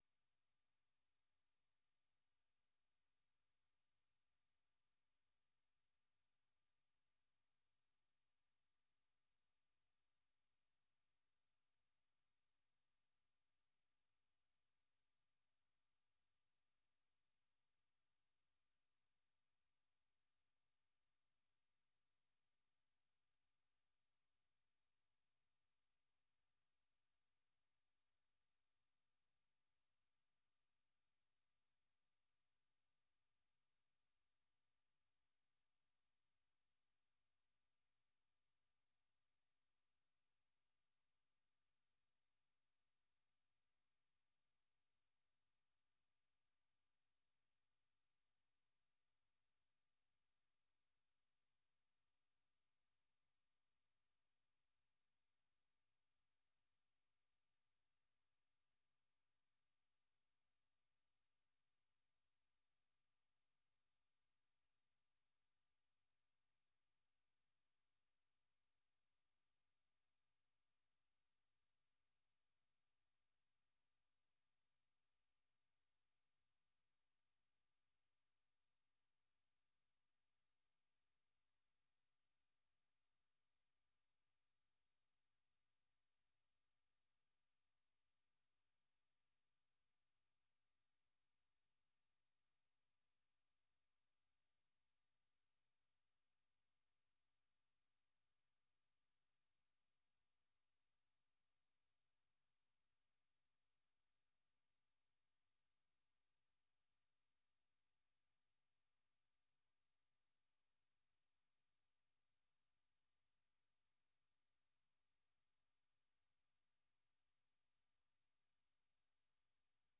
The special broadcast was recorded from the RNE stall in the park.